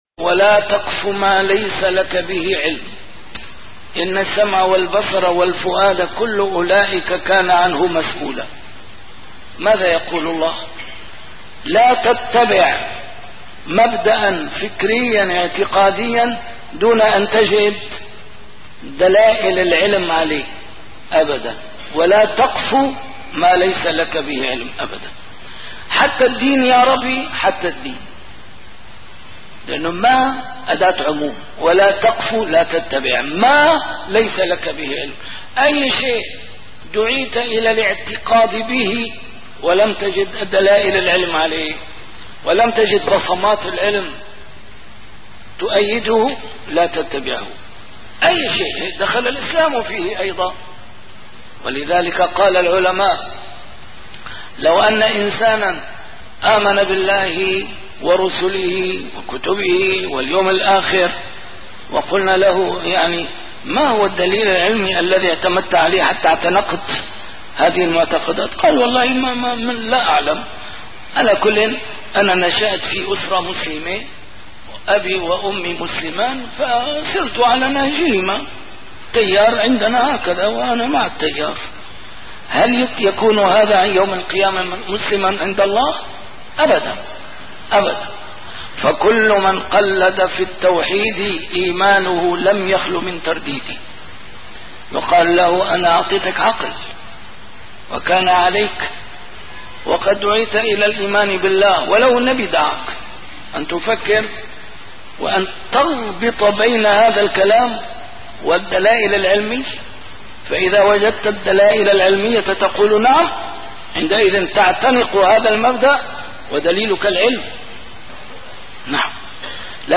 محاضرات متفرقة في مناسبات مختلفة - A MARTYR SCHOLAR: IMAM MUHAMMAD SAEED RAMADAN AL-BOUTI - الدروس العلمية - أساسيات العقيدة الإسلامية في أقل من ساعتين